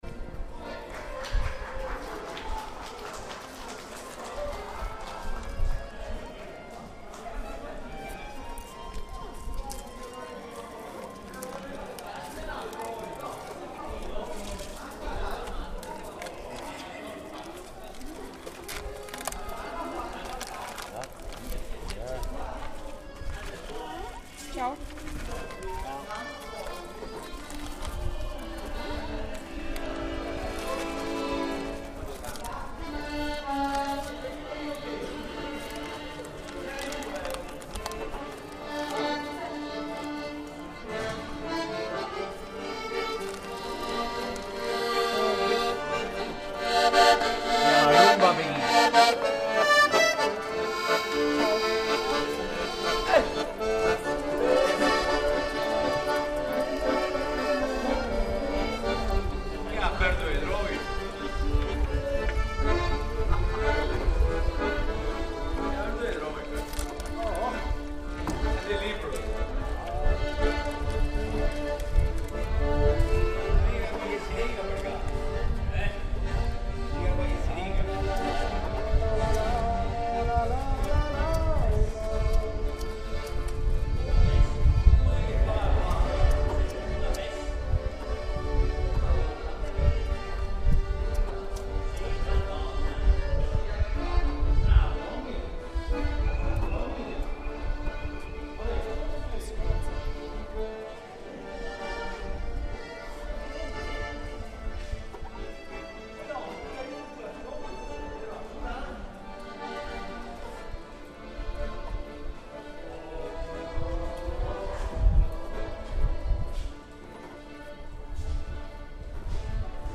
Gondolas on Venetian canals